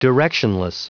Prononciation du mot directionless en anglais (fichier audio)
Prononciation du mot : directionless